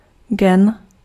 Ääntäminen
Ääntäminen Haettu sana löytyi näillä lähdekielillä: tšekki Käännös Konteksti Ääninäyte Substantiivit 1. gene genetiikka US Suku: m .